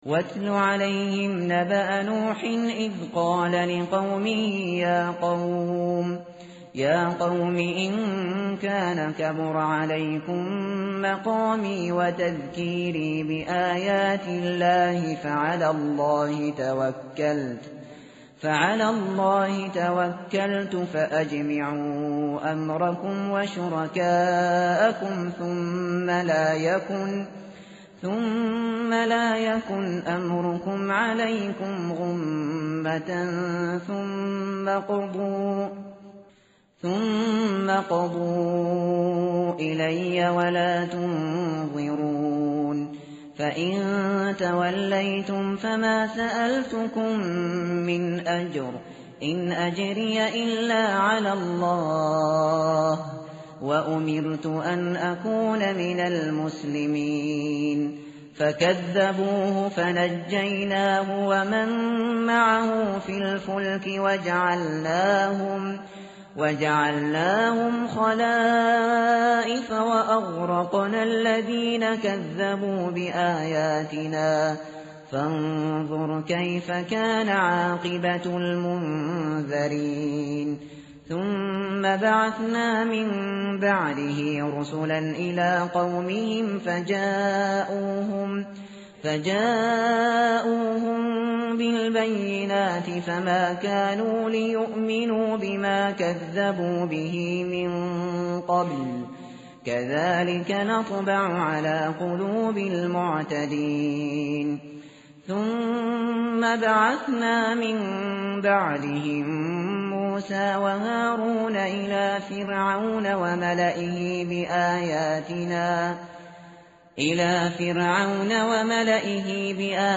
متن قرآن همراه باتلاوت قرآن و ترجمه
tartil_shateri_page_217.mp3